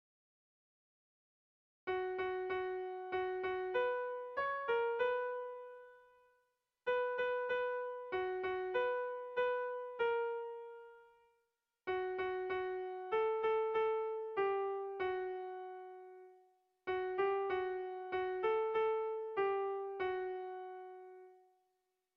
Sehaskakoa
ABDE